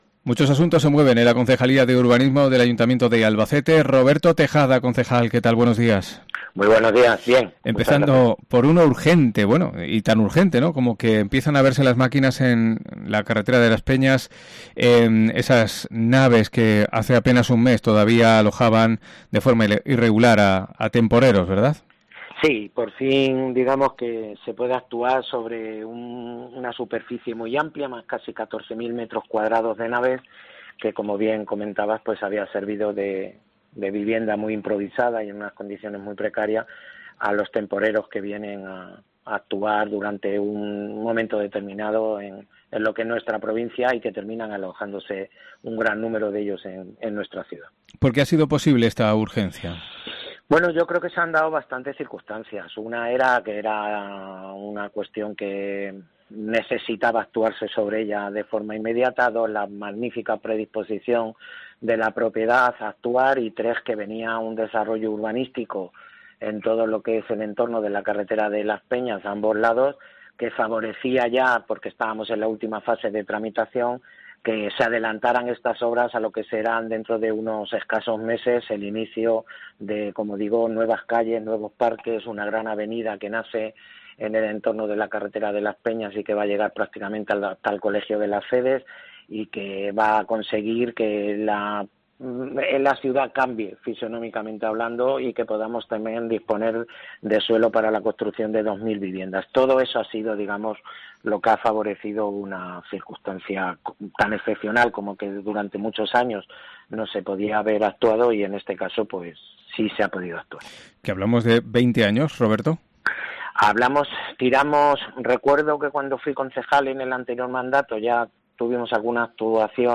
AUDIO: Roberto Tejada, concejal de Urbanismo, nos da un buen puñado de novedades urbanísticas de la ciudad de Albacete
ENTREVISTA CONCEJAL URBANISMO